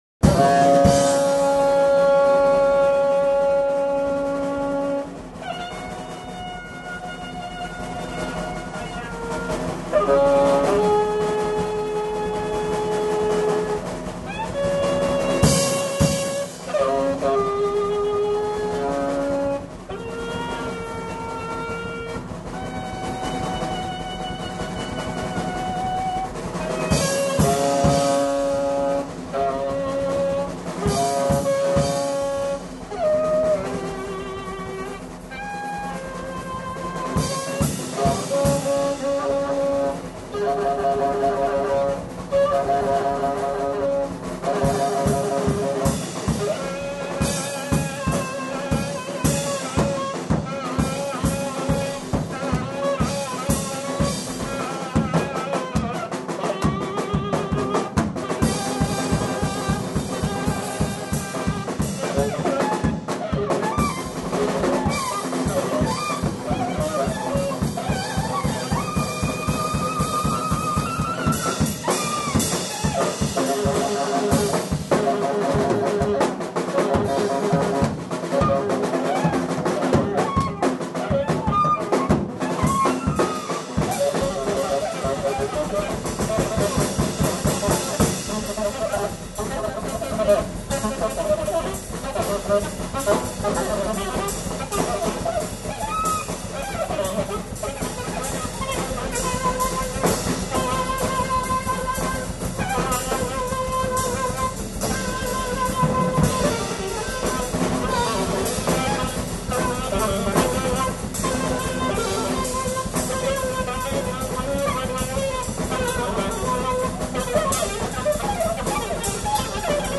sax.
bat.